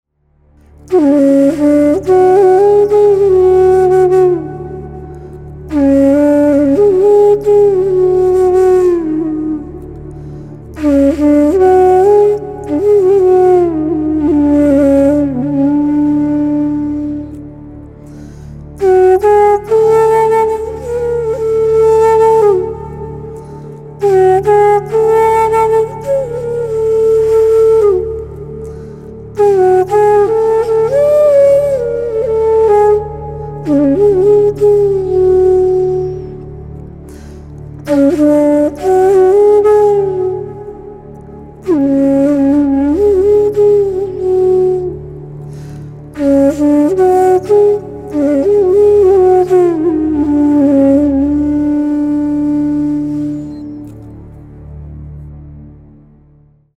MOVEMENT-01-THEME-FLUTE-MASTER-FOR-EDIT-1.mp3